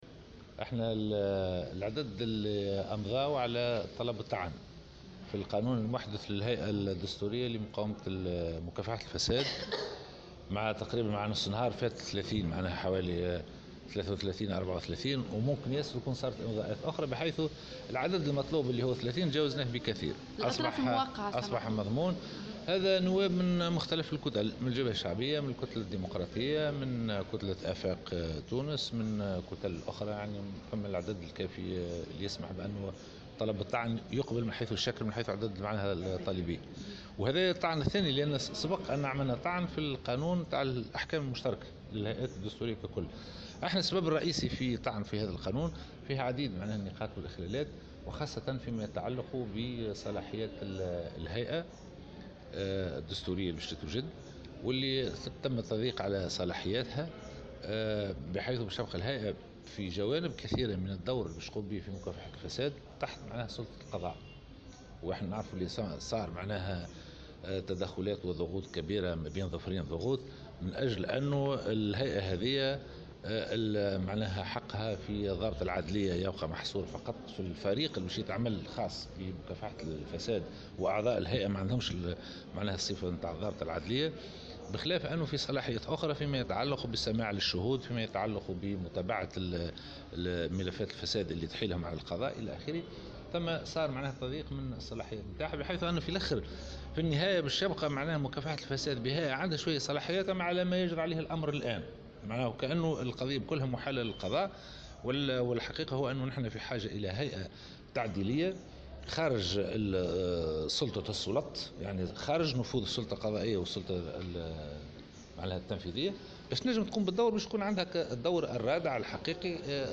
أكد النائب عن الجبهة الشعبية الجيلاني الهمامي في تصريح لمراسلة الجوهرة"اف ام" أن عدد الامضاءات لطلب الطعن في القانون المحدث للهيئة الدستورية لمكافحة الفساد تجاوز 33 توقيعا من مختلف الكتل ، الجبهة الشعبية ، الكتلة الديمقراطية،افاق تونس و غيرهم.